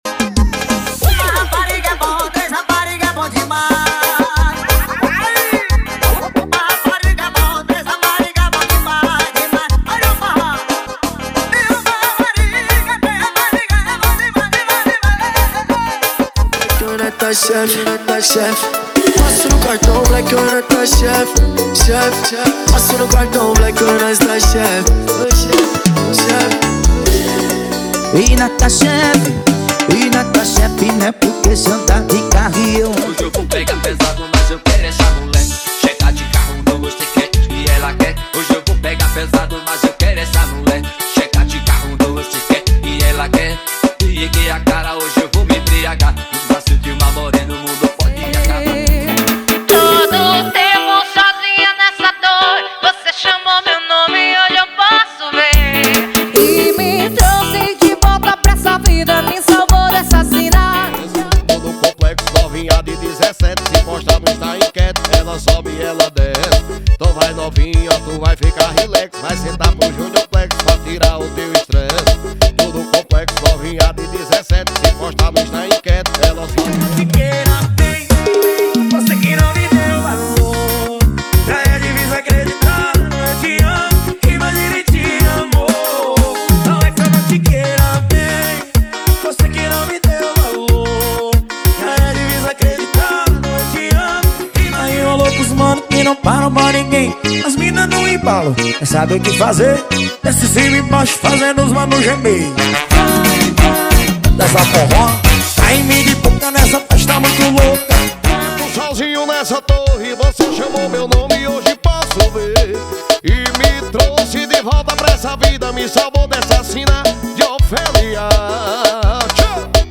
✅ Músicas sem vinhetas
Alta qualidade de áudio
✔ Quem toca sertanejo, piseiro e forró
🔥 Mais animação na pista